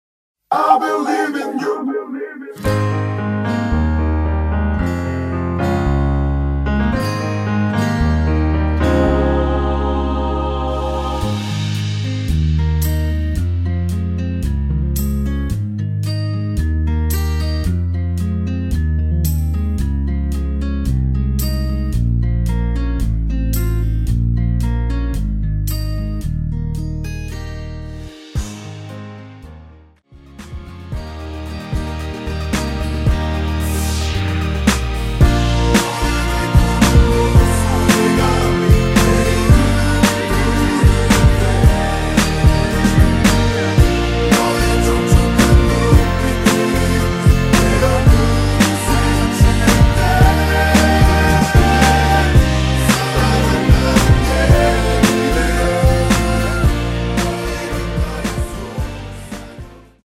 (-2) 내린 코러스 포함된 MR 입니다.(미리듣기 참조)
Ab
◈ 곡명 옆 (-1)은 반음 내림, (+1)은 반음 올림 입니다.
앞부분30초, 뒷부분30초씩 편집해서 올려 드리고 있습니다.
중간에 음이 끈어지고 다시 나오는 이유는